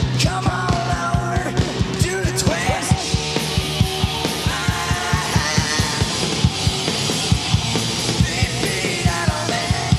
No. This is a soundboard recording.